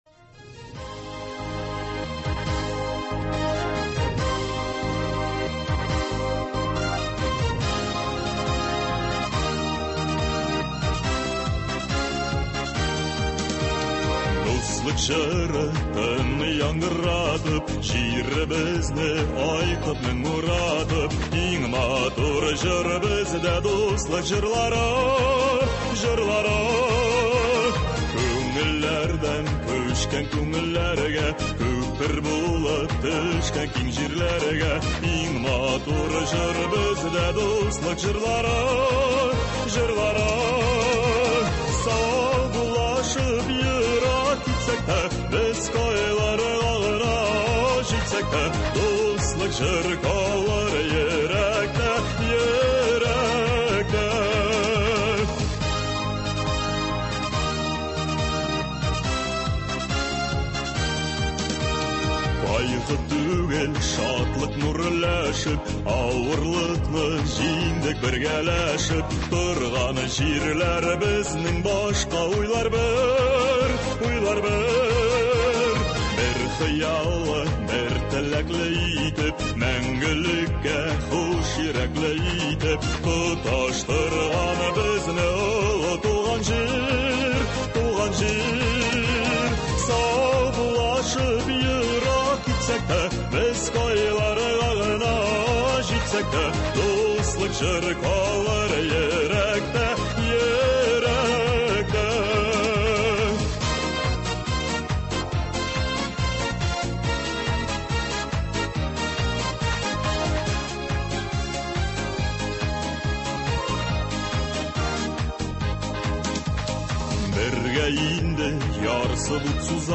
тыңлаучылар сорауларына җавап бирәчәк.